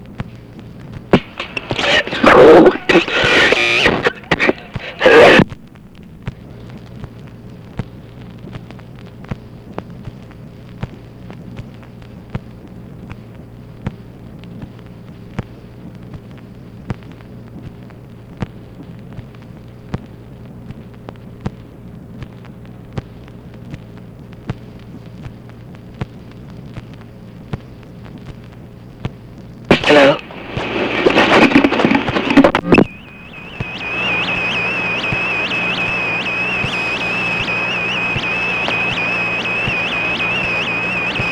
OFFICE NOISE, December 1, 1966
Secret White House Tapes | Lyndon B. Johnson Presidency